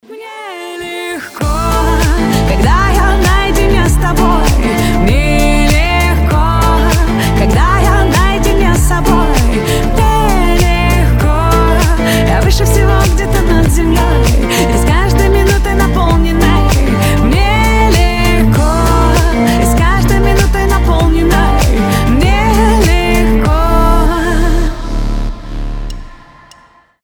• Качество: 320, Stereo
позитивные
женский голос
вдохновляющие
легкие